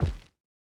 Rubber_04.wav